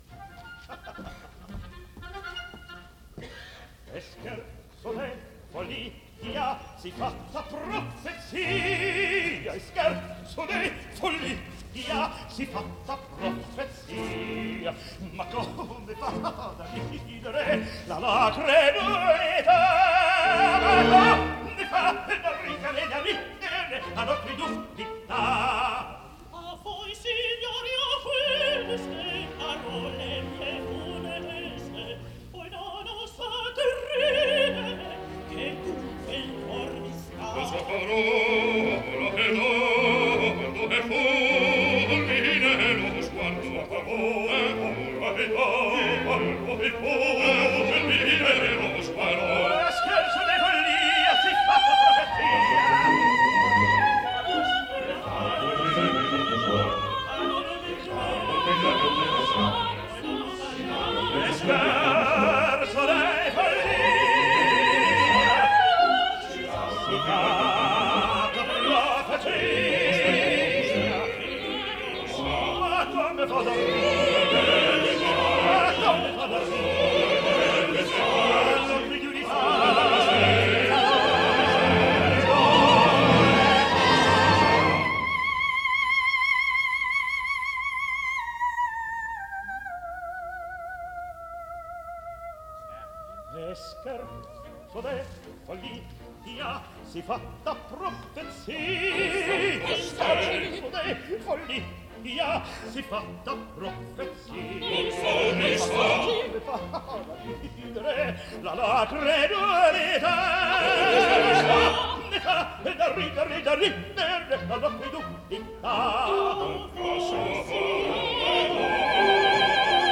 Запись 10 декабря 1955 года, Metropolitan Opera.